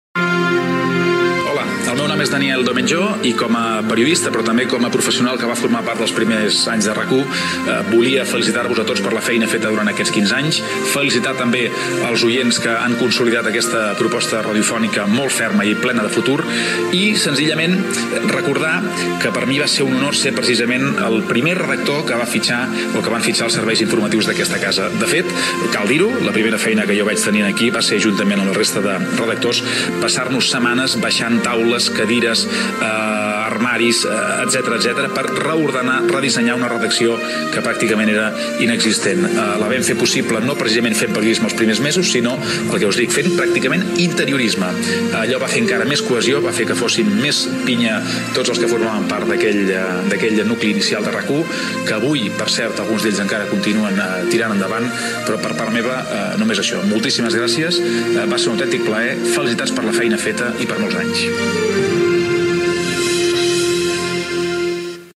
Felicitacions pels 15 anys de RAC 1.